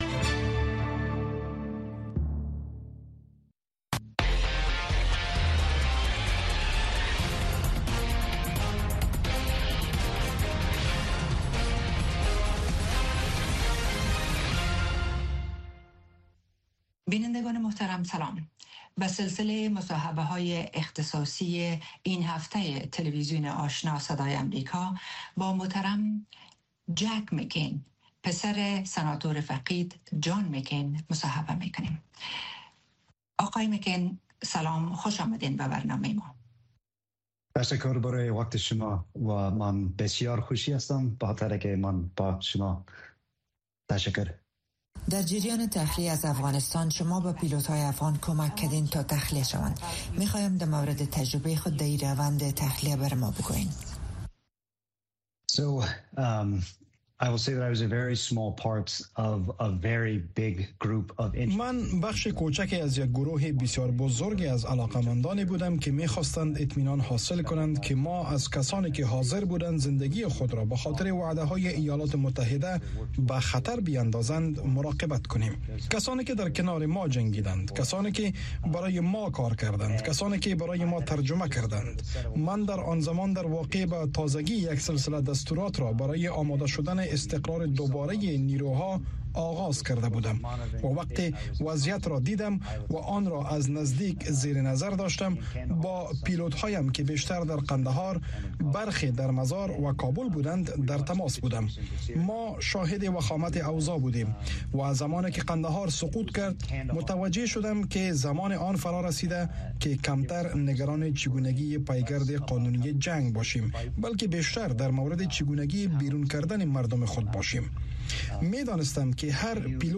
گفتگو‌های ویژه با مسوولان، مقام‌ها، کارشناسان و تحلیلگران در مورد مسایل داغ افغانستان و جهان را هر شنبه در نشرات ماهواره‌ای و دیجیتلی صدای امریکا دنبال کنید.